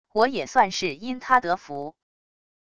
我也算是因他得福wav音频生成系统WAV Audio Player